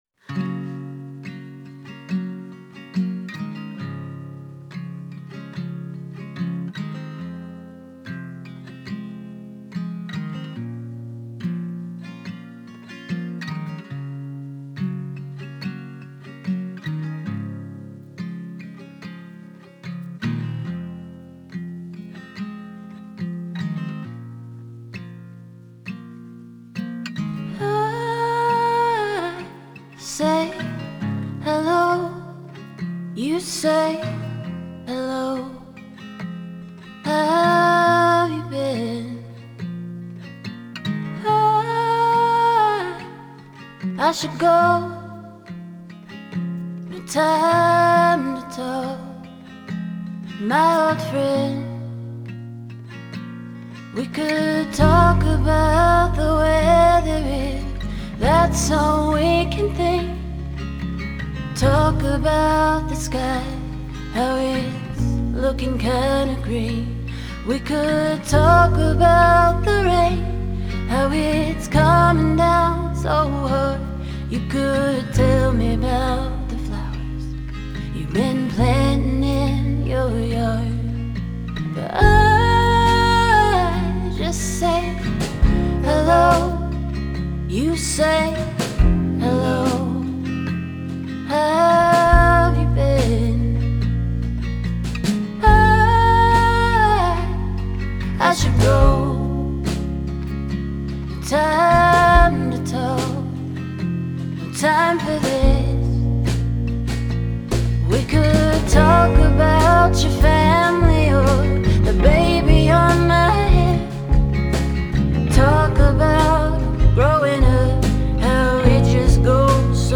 Genre: Folk Pop, Americana, Singer-Songwriter